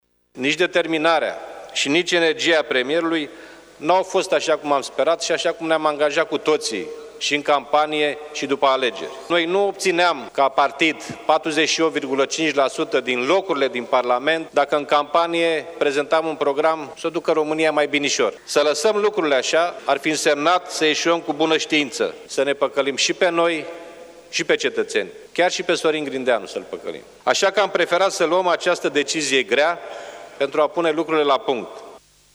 Președintele Camerei Deputaților, Liviu Dragnea, a declarat că nu și-a dorit niciodată să se ajungă la situația actuală, afirmând că adoptarea moțiunii de cenzură înseamnă continuarea guvernării PSD – ALDE așa cum au vrut românii la alegerile din decembrie 2016: